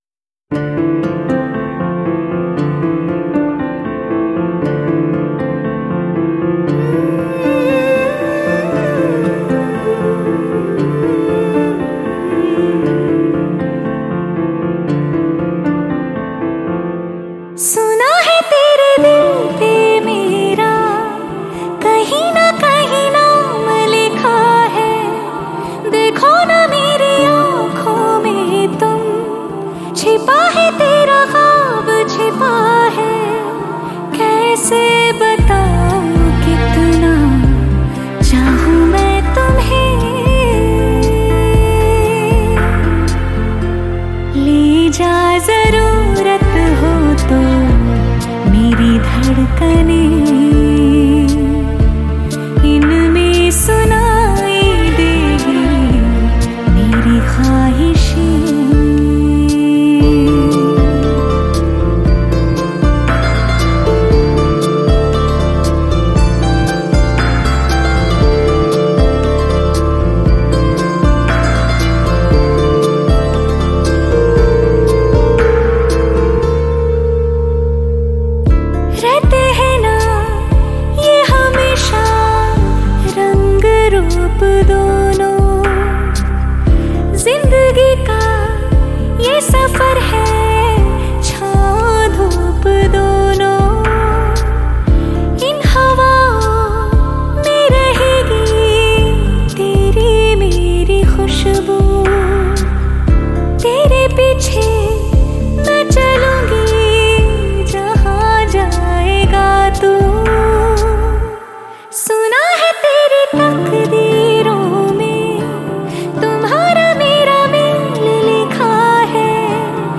2021 Bollywood Mp3 Music